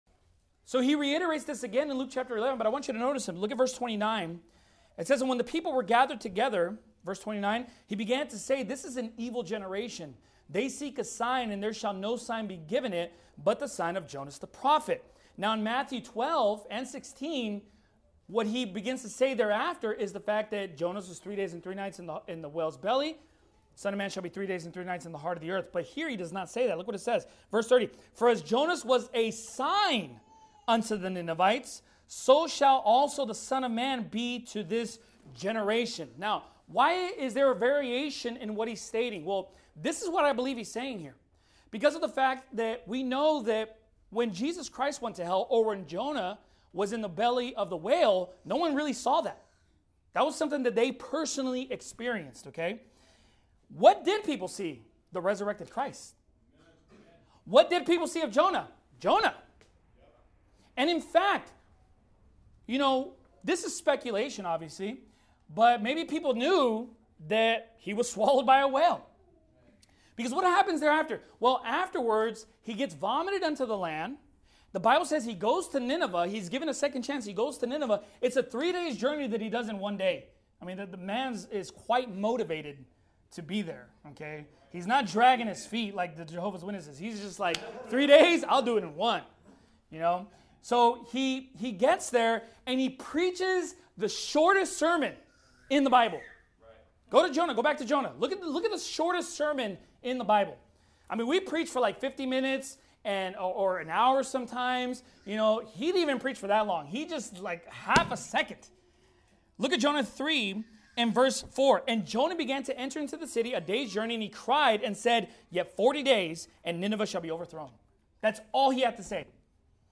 The Shortest Sermon In The Bible